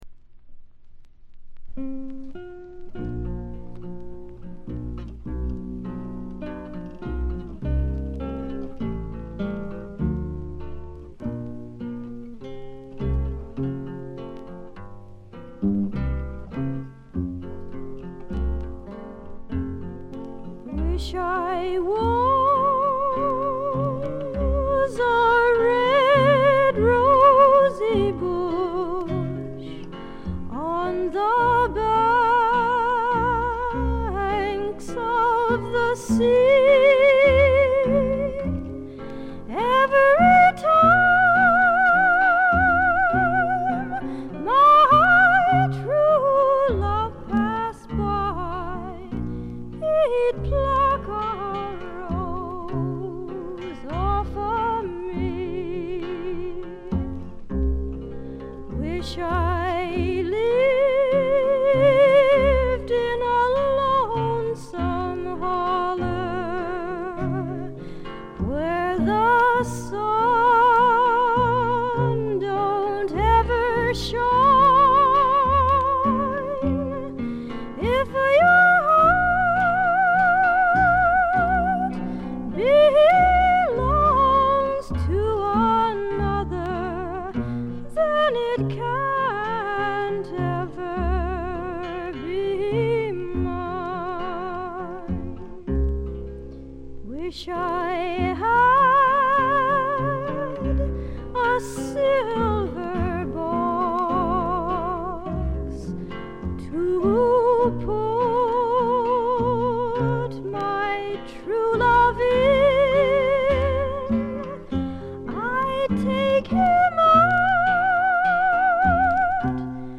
軽微なバックグラウンドノイズやチリプチ。
美しくも素朴な味わいのあるソプラノ・ヴォイスに癒やされてください。
試聴曲は現品からの取り込み音源です。